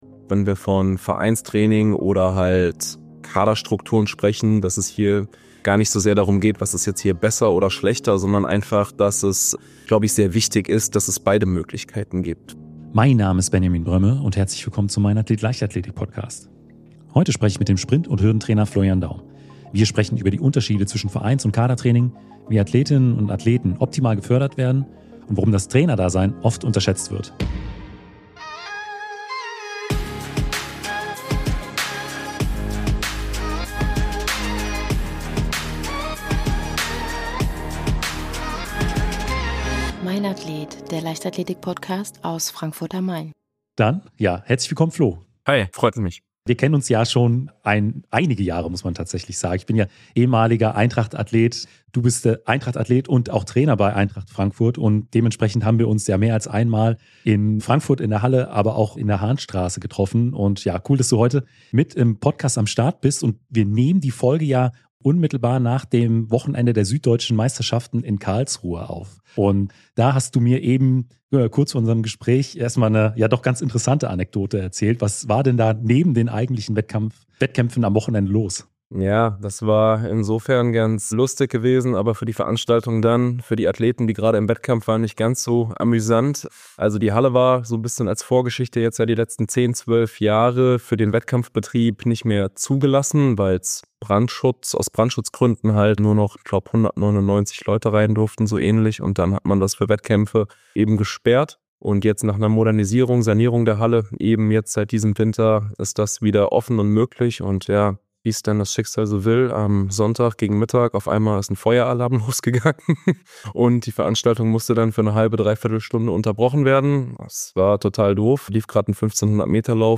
Im Mainathlet Leichtathletik Podcast geht es rund um die Leichtathletik und die Athleten aus allen Disziplinen. Ich werde regelmäßig aktive und ehemalige deutsche Leichtathleten und Leichtathletinnen interviewen.